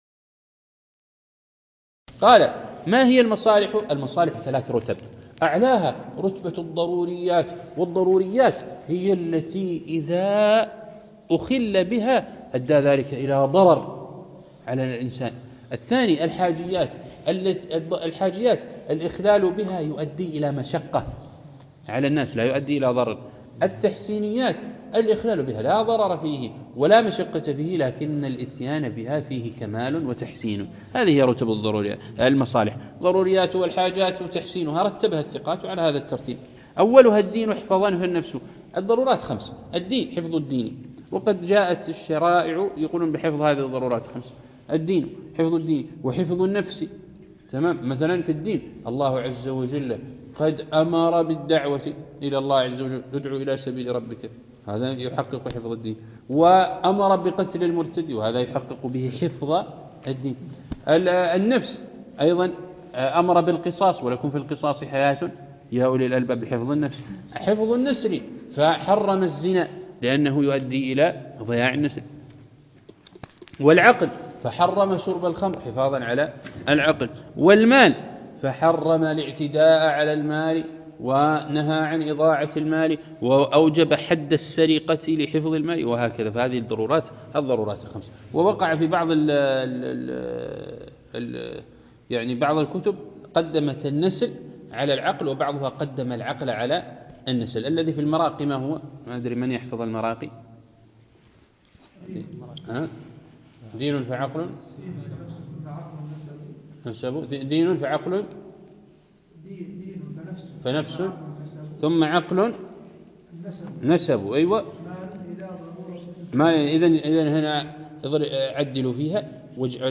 عنوان المادة الدرس (18) شرح النظم المعسول في تعليم الأصول